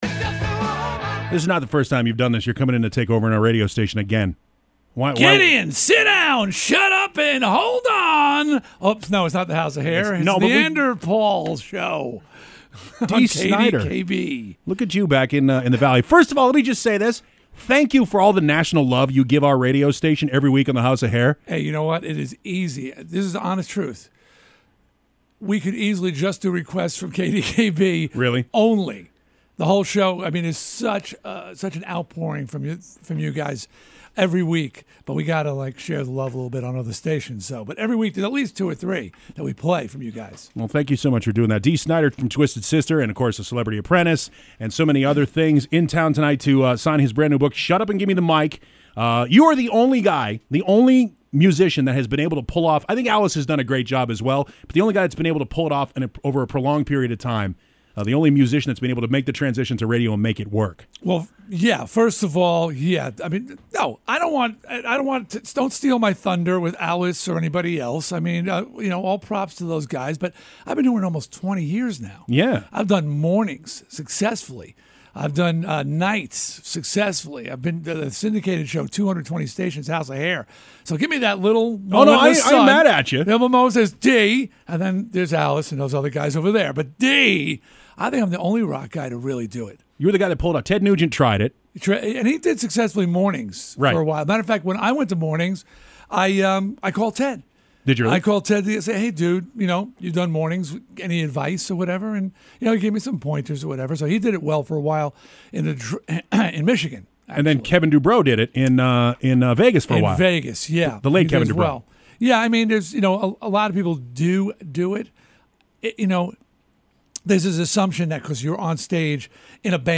This interview took place right before the band rolled into Phoenix to spend St. Patrick’s Day with us. We spoke for a *lot* longer than what you hear here, but I edited out our discussion of Nothern Irleand. You’ll hear the Bono reference at the beginning.